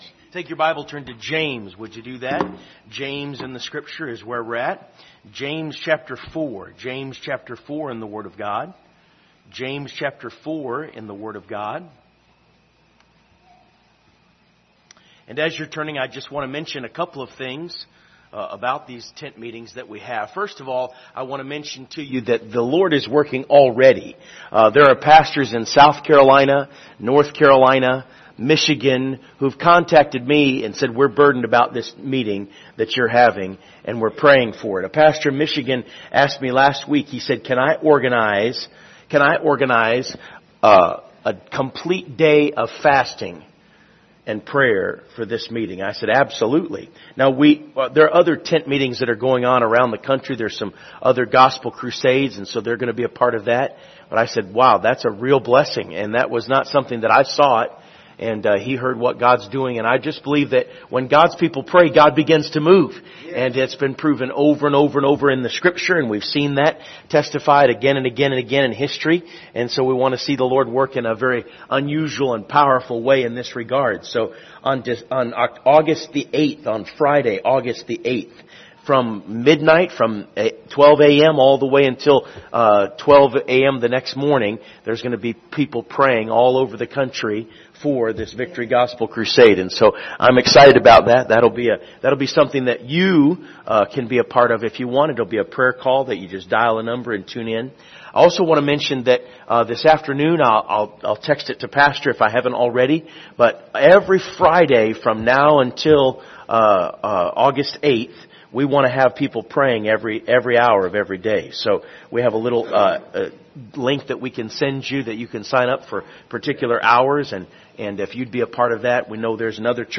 Passage: James 4:1 Service Type: Sunday Morning Topics